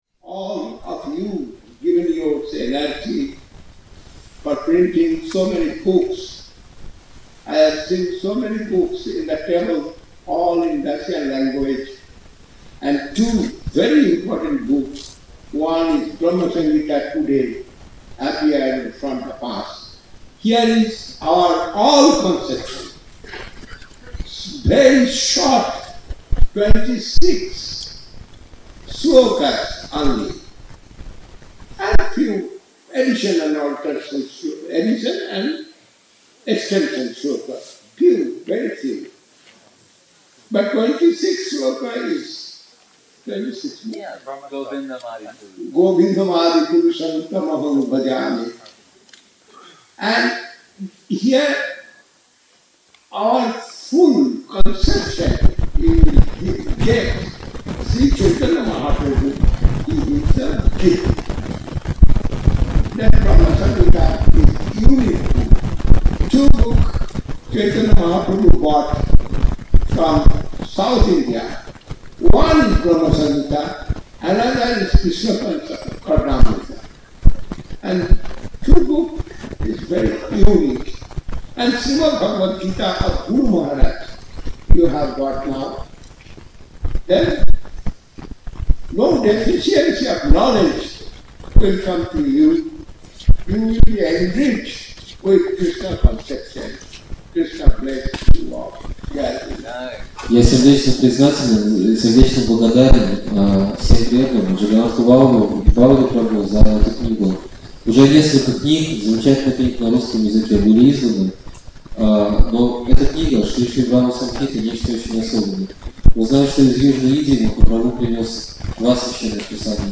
Киртан "Джая Радха-Мадхава".
Place: Sri Chaitanya Saraswat Math Saint-Petersburg